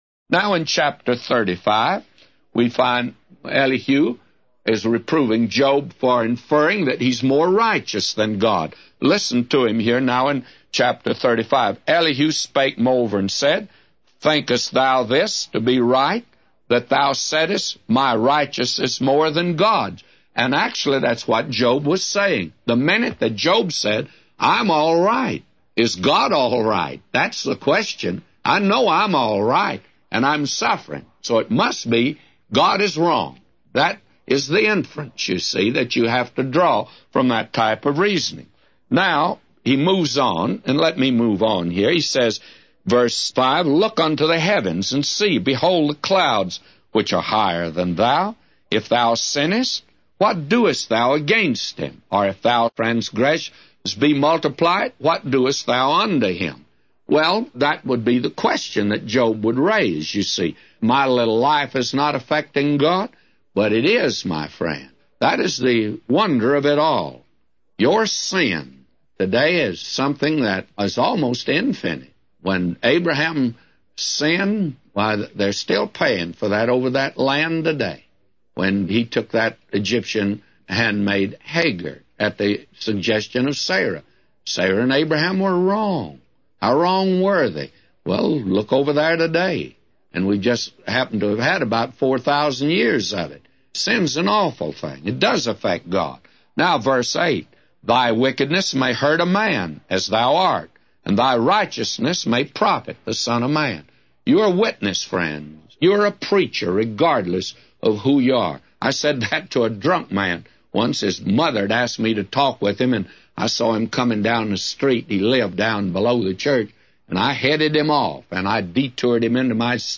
A Commentary By J Vernon MCgee For Job 35:1-999